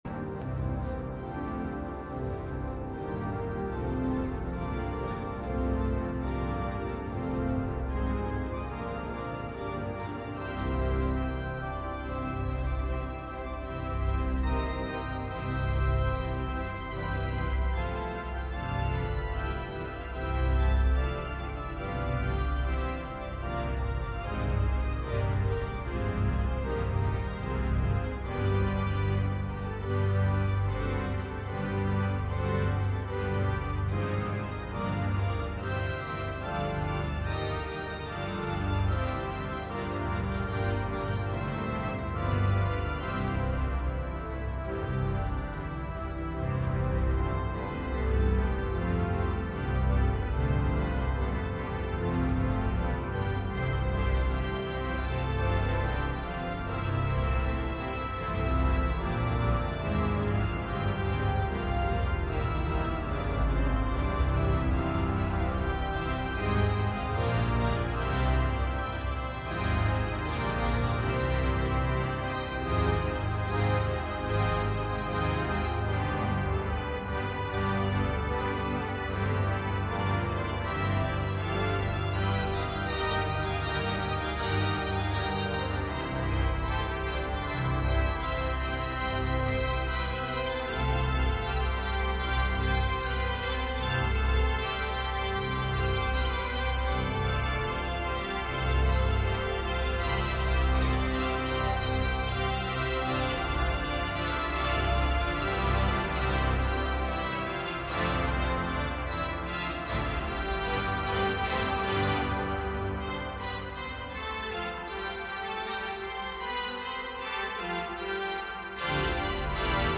Pontifikalamt aus dem Kölner Dom am Hochfest der ohne Erbsünde empfangenen Jungfrau und Gottesmutter Maria mit Erzbischof Rainer Maria Kardinal Woelki.